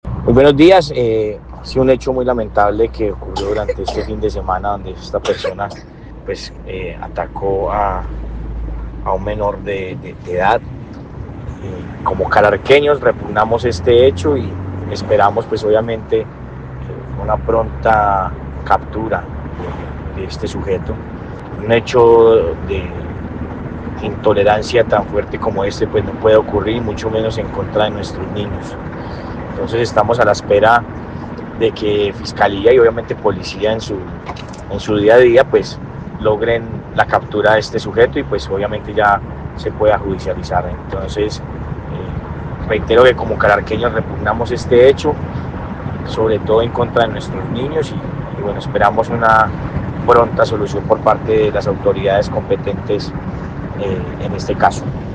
Alcalde de Calarcá, Sebastián Ramos